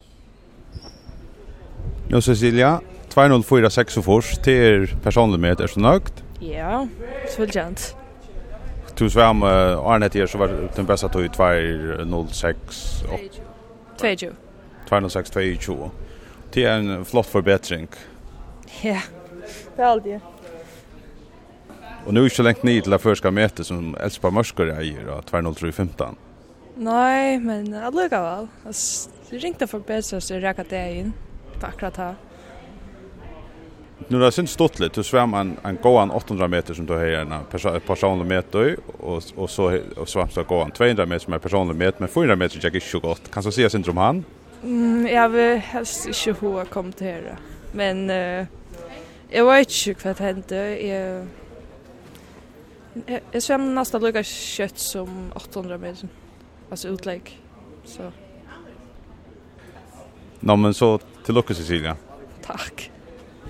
Samrøða